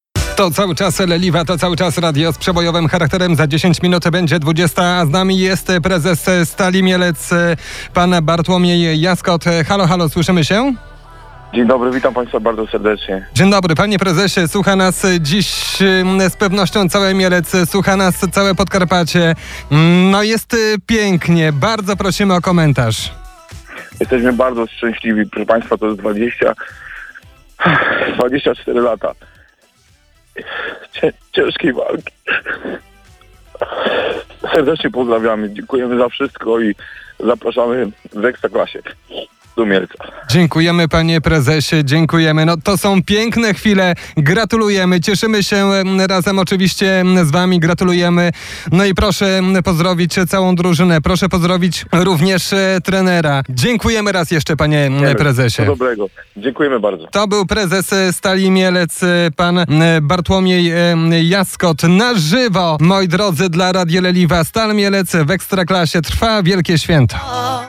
w rozmowie z Radiem Leliwa po wygranym meczu nie krył emocji: